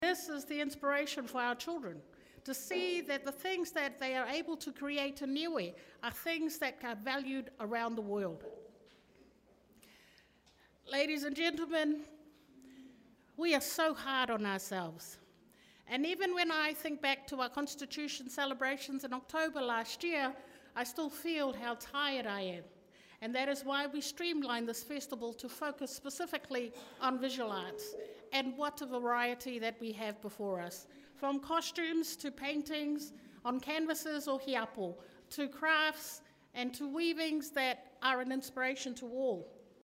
It was a full house as people turned up to the official opening of the Niue Arts Festival 2025 in their colorful attires at the old Fale Fono Foyer on Tuesday night.